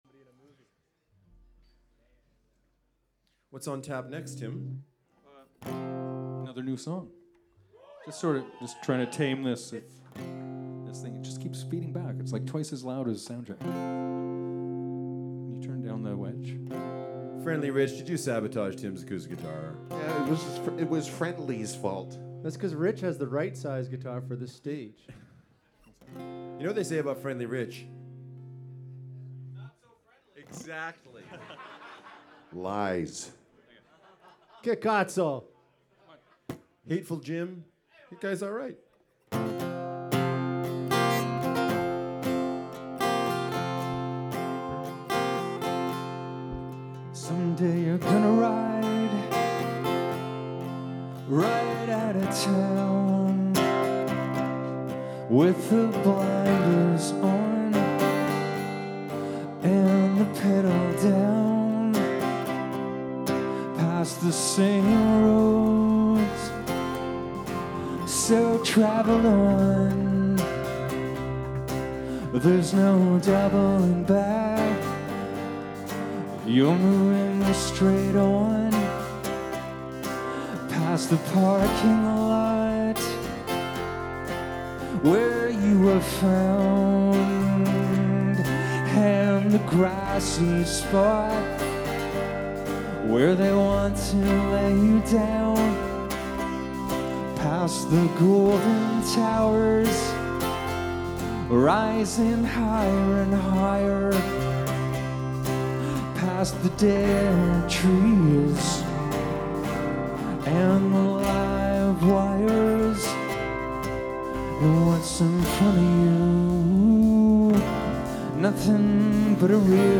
The Horseshoe Tavern Toronto May 24 2017
Soundboard > Edirol R-44 > direct to SD > flac/wav/mp3
Violin
Keyboards